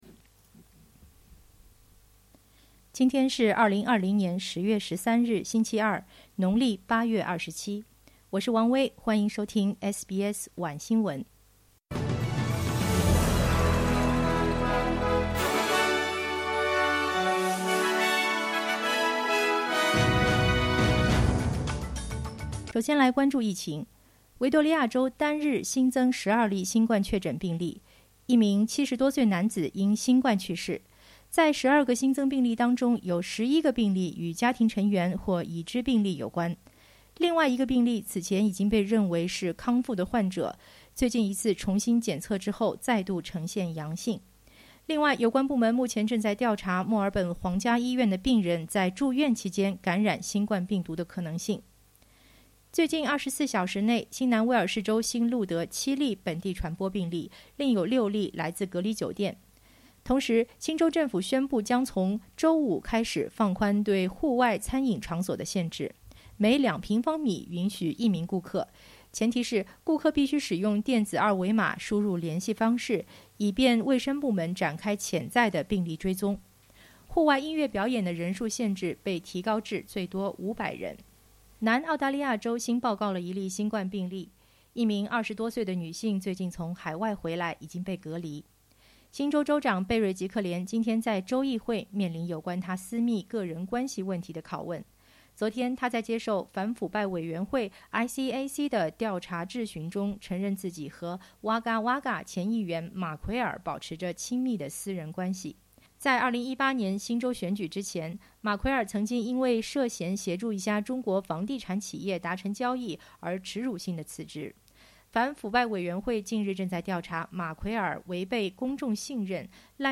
SBS晚新闻（2020年10月13日）